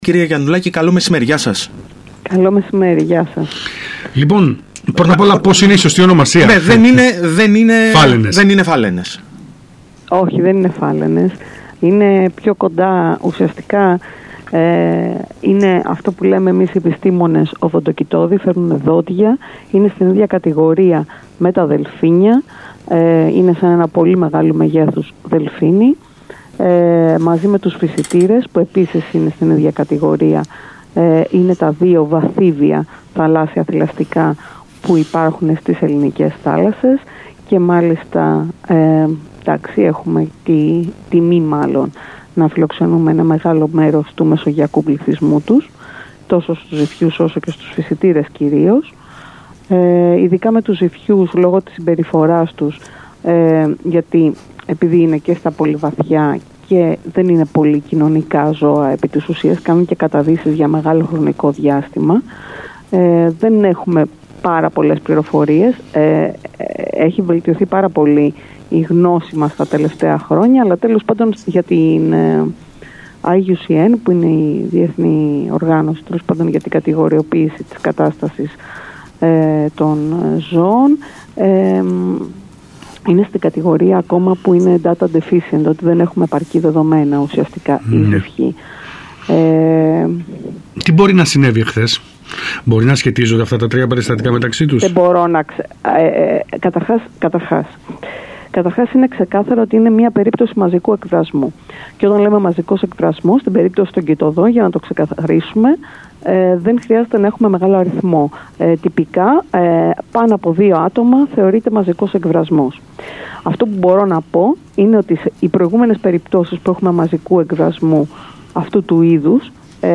Το φαινόμενο του μαζικού εκβρασμού των φαλαινών που… δεν ήταν φάλαινες, στα νότια της Κρήτης μίλησε στον ΣΚΑΙ Κρήτης 92.1